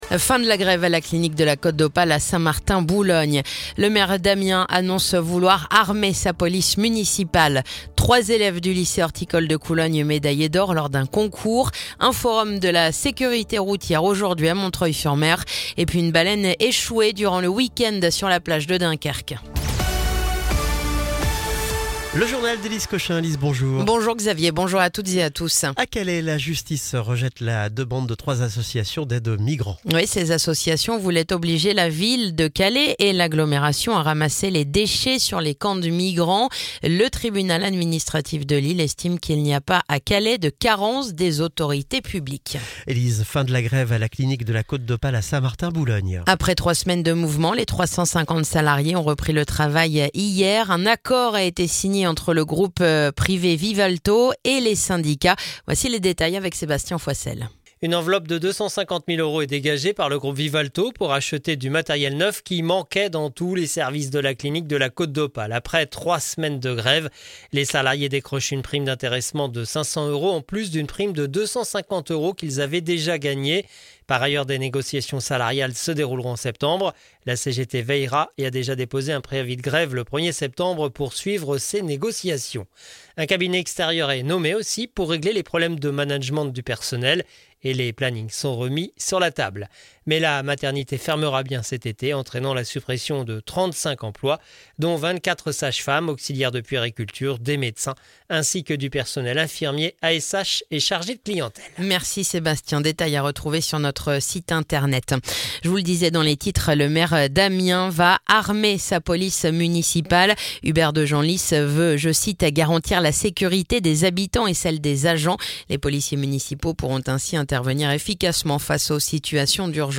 Le journal du mercredi 11 juin